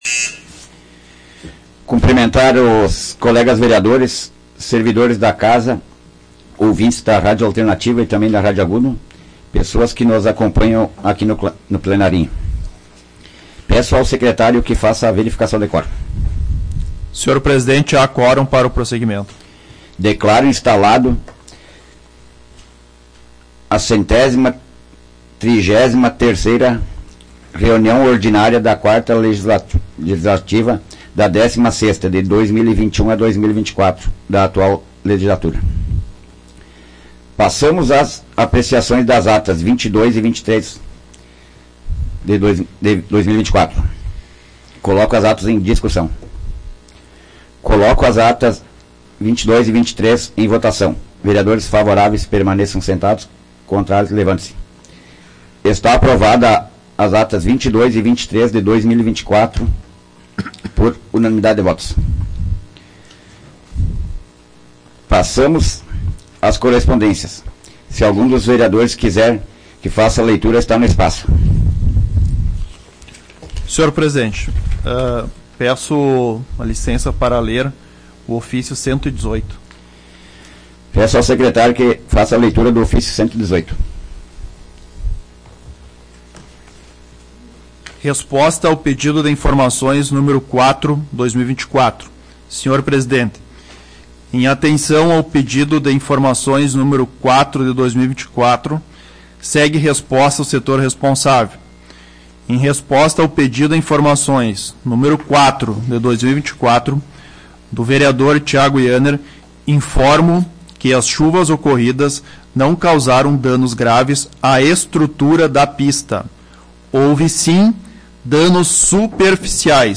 Áudio da 133ª Sessão Plenária Ordinária da 16ª Legislatura, de 27 de maio de 2024